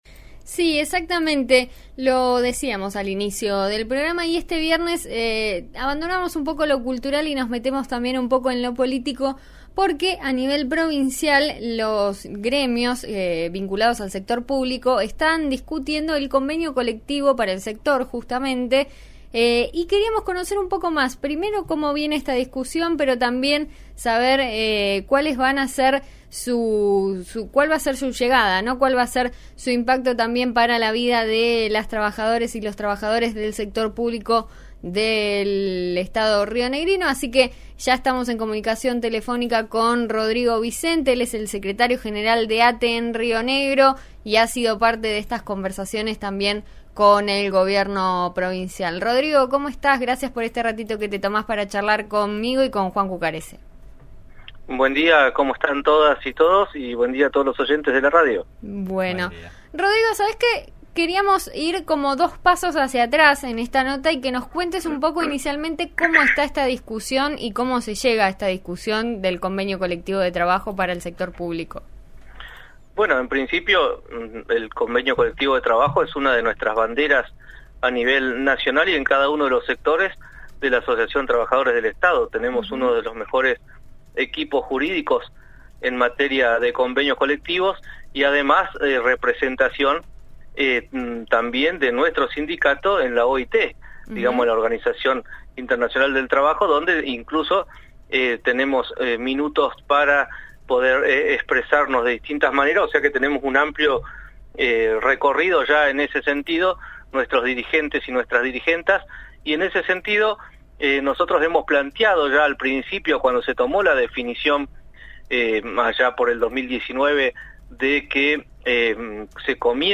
en diálogo con «En Eso Estamos» (RN RADIO)